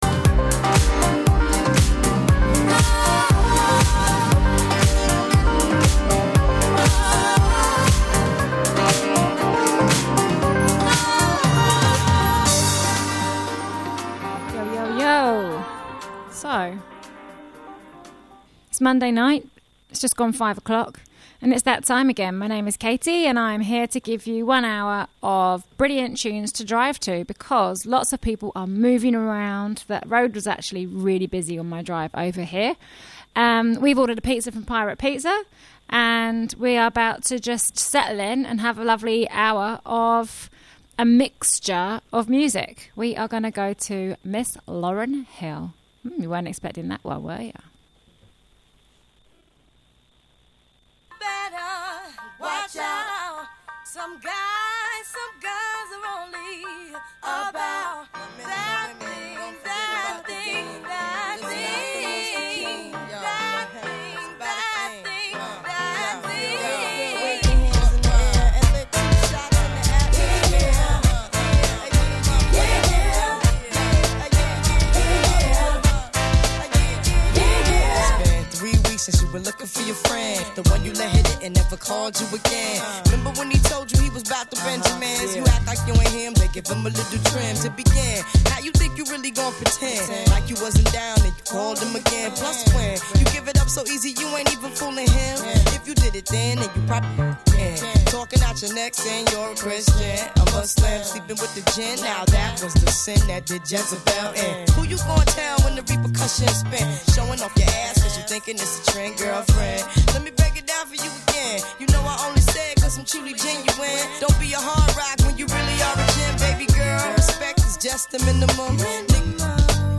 Pizza in the studio with my tribe of small humans - Hibernating with good music HAPPY MIDWINTER with a wildly eclectic show